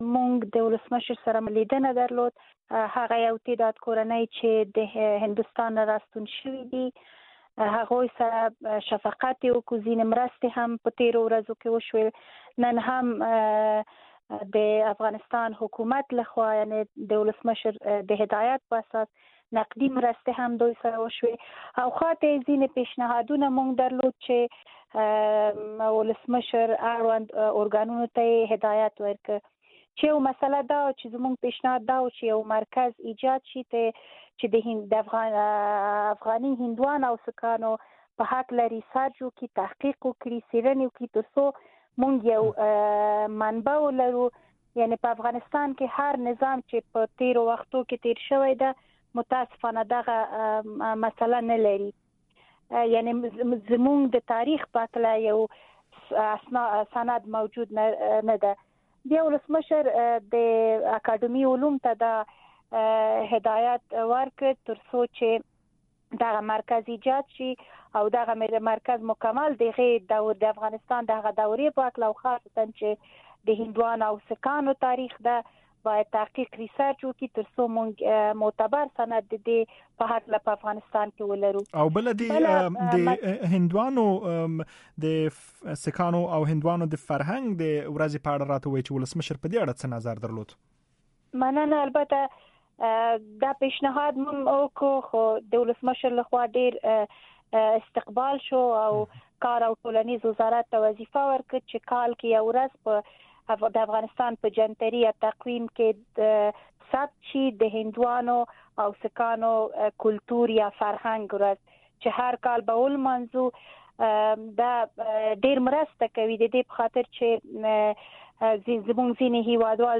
له انارکلي هنریار سره مرکه